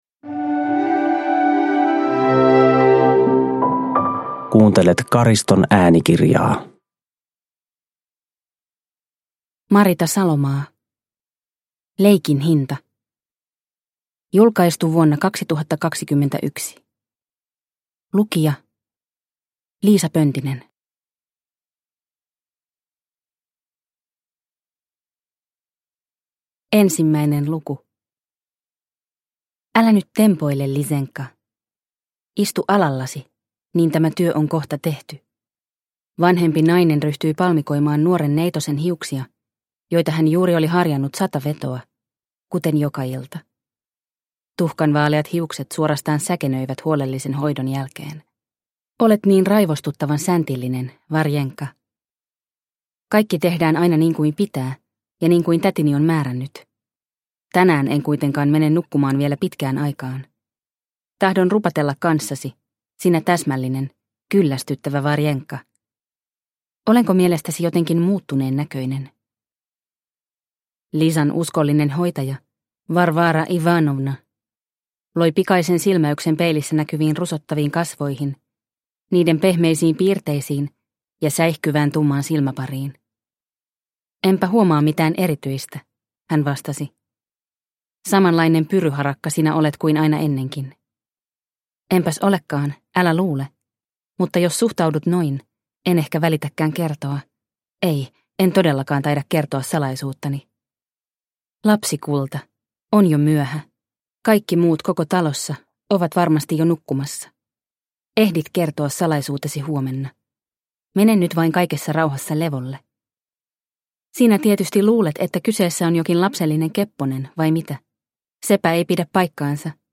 Leikin hinta – Ljudbok – Laddas ner